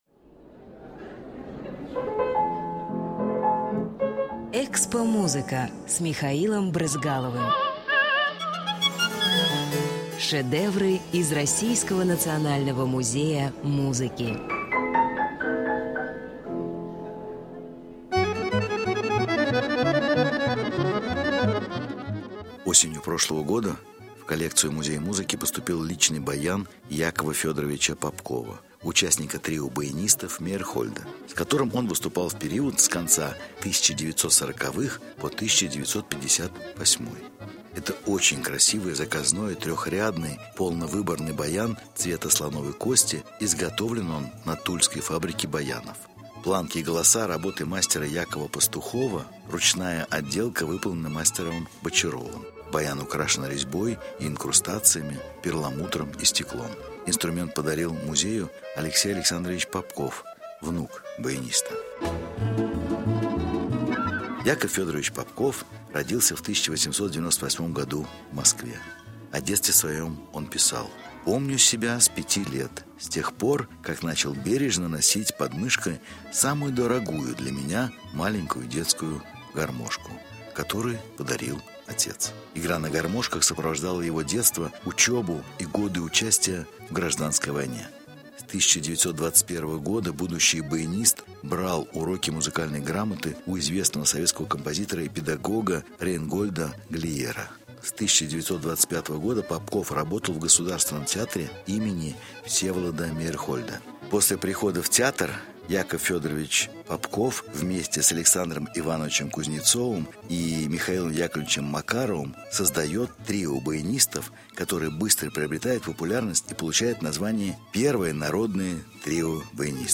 Баян
accordion.mp3